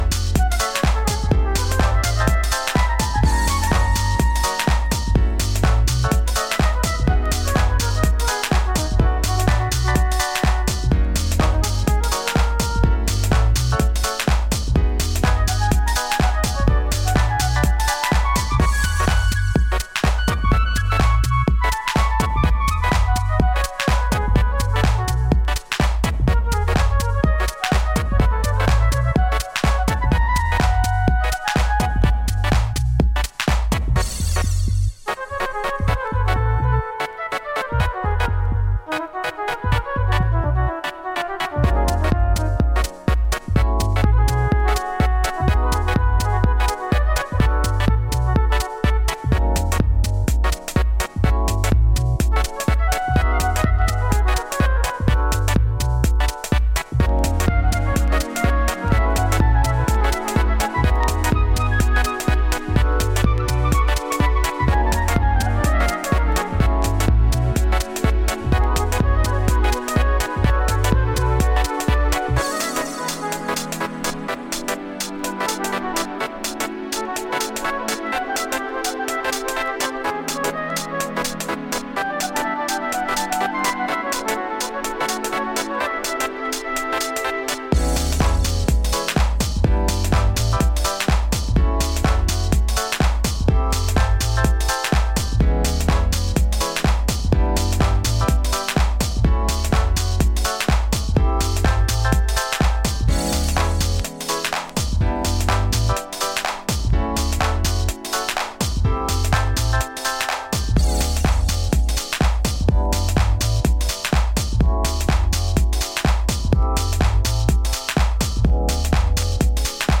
a deep, warm bass, dope keys and lots of shuffle!
a flying jazzy flute solo!